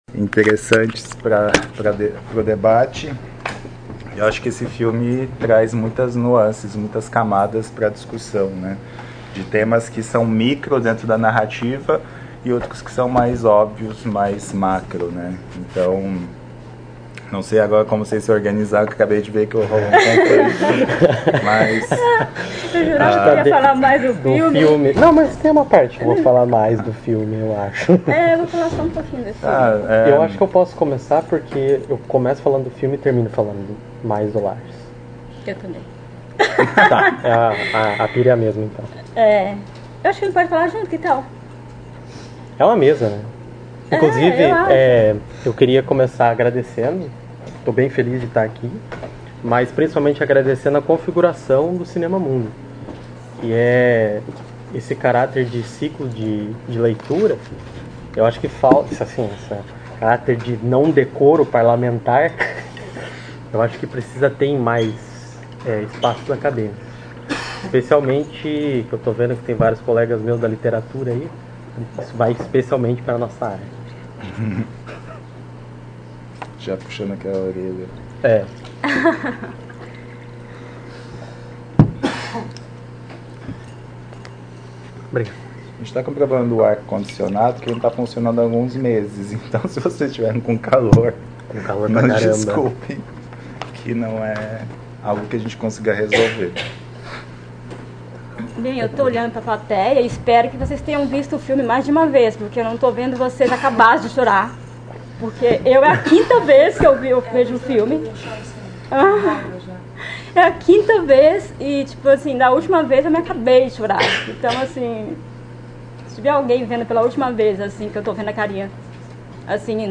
Comentários dos debatedore(a)s convidado(a)s
realizada em 26 de setembro de 2019 no Auditório Elke Hering da Biblioteca Central da UFSC